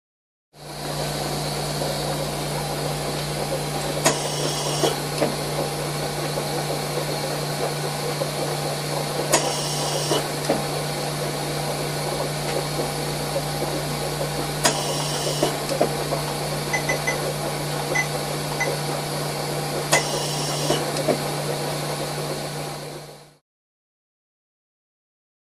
Pleurovac; Repeated Oxygen Pumps; Water / Motor Noise, Computer Beeps, Medium Perspective. Hospital.